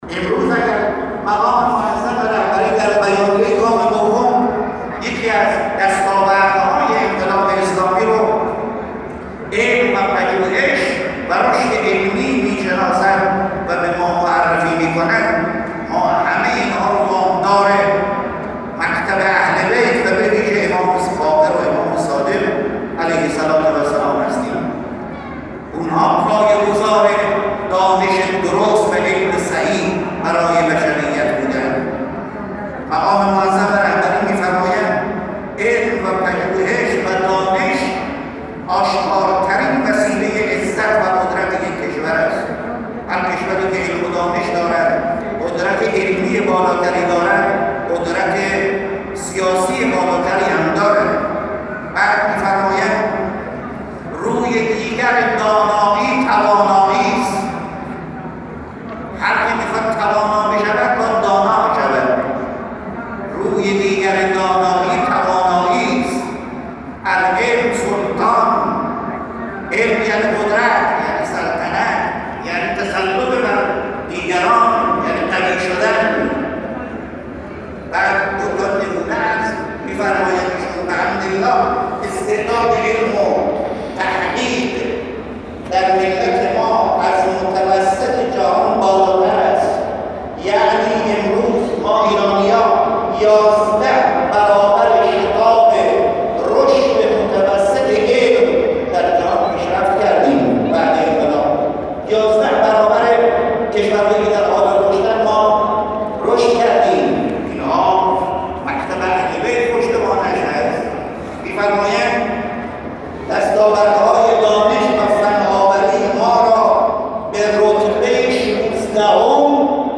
به گزارش خبرنگار خبرگزاری رسا در خراسان شمالی، حجت‌الاسلام والمسلمین ابوالقاسم یعقوبی، نماینده ولی فقیه در خراسان شمالی و امام جمعه بجنورد، امروز در خطبه‌های نماز عبادی سیاسی جمعه این هفته مرکز استان، با گرامیداشت سالگرد شهادت امام محمدباقر (ع)، اظهار کرد: این امام همام شکافنده علوم بود و با این اقدام اذهان بشریت به‌ویژه امت اسلام را بر روی معارف والا و متعالی اسلام گشود.